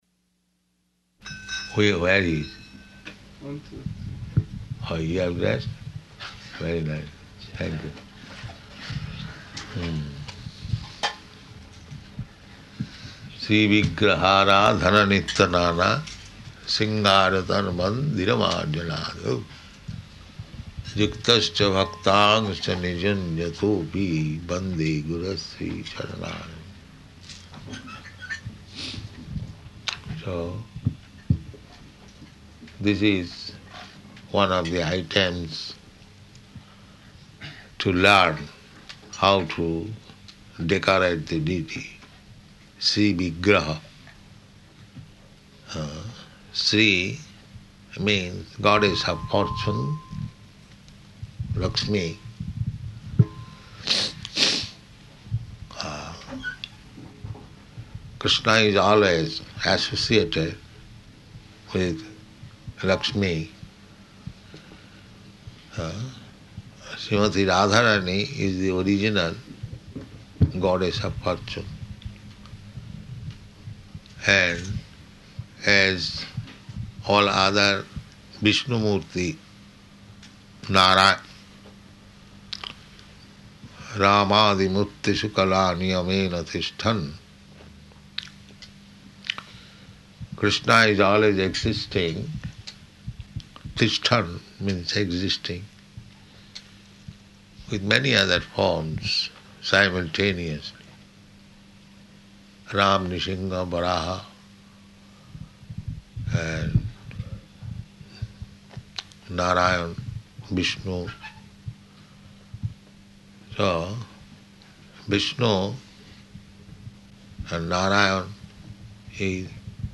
Departure Lecture
Departure Lecture --:-- --:-- Type: Lectures and Addresses Dated: March 12th 1975 Location: London Audio file: 750312DP.LON.mp3 Prabhupāda: ...where he is?